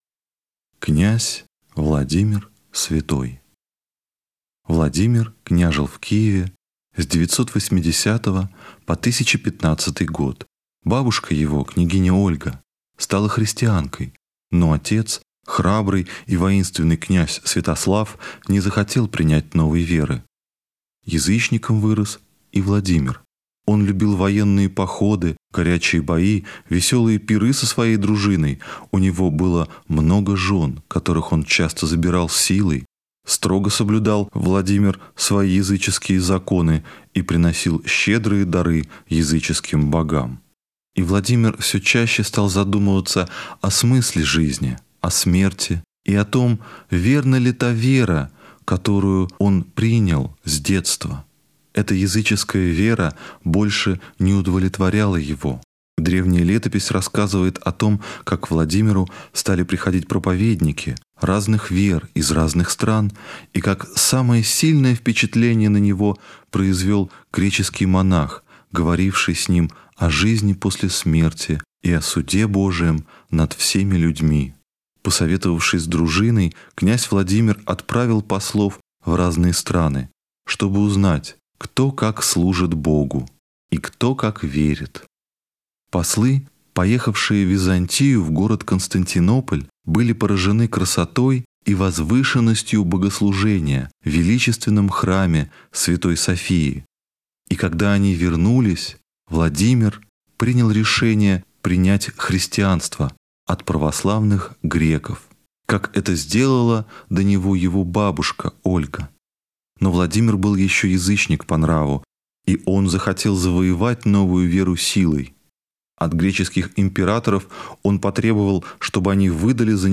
Князь Владимир святой - аудио рассказ - слушать онлайн